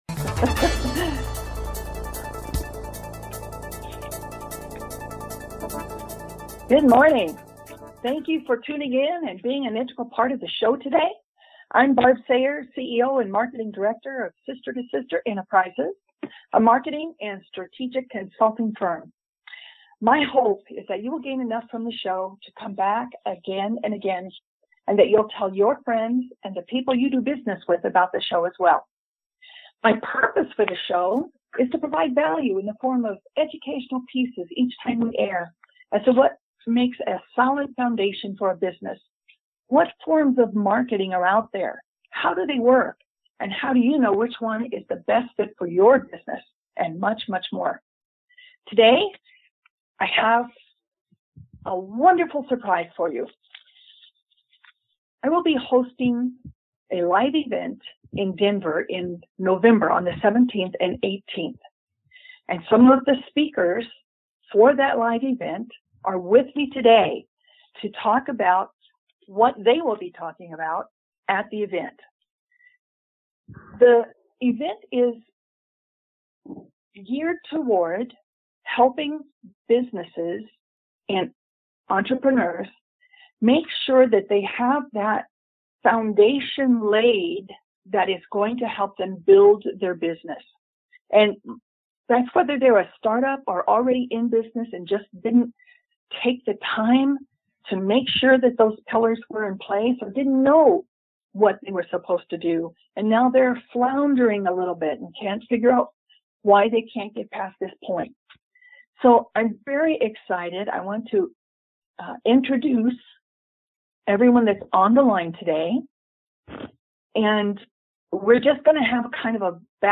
Bizz Buzz is a talk show, crafted to provide unique, powerful marketing techniques that can positively impact your businesses bottom line, in sales and customer acquisition.
Call-ins encouraged!